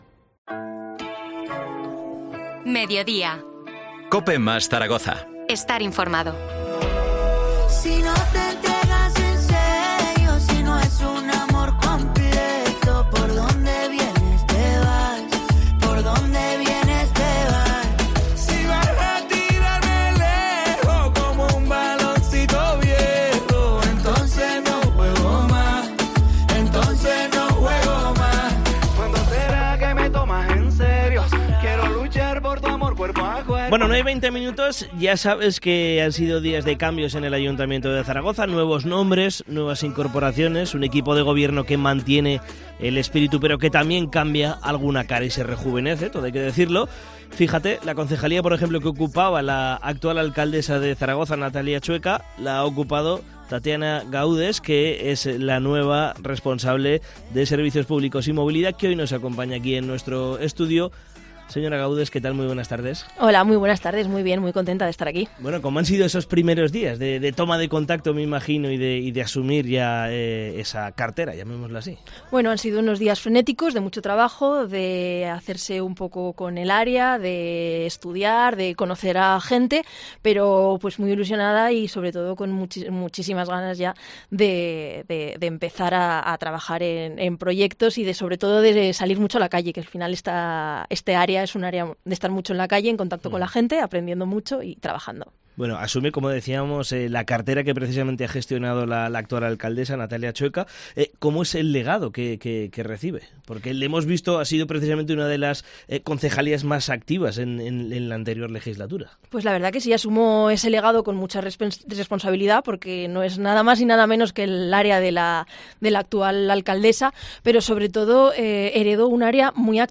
Entrevista a Tatiana Gaudes, consejera de Medio Ambiente y Movilidad